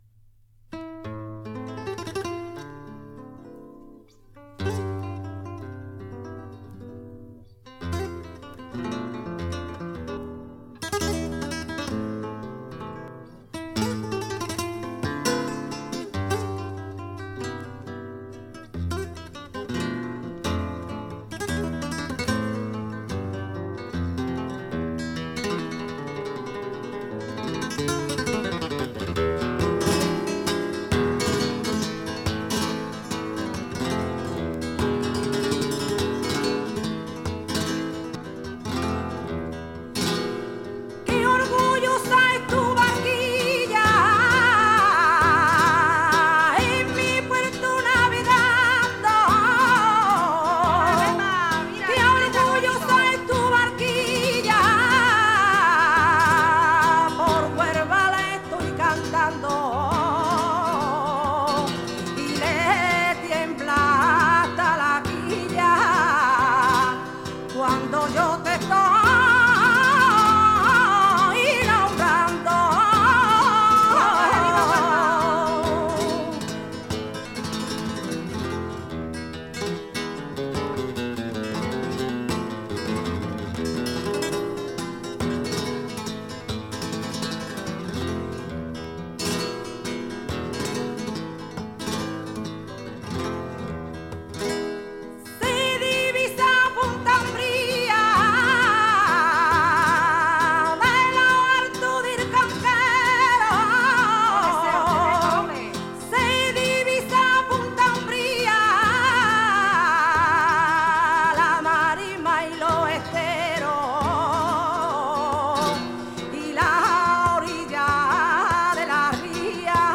fandangos de Huelva
chant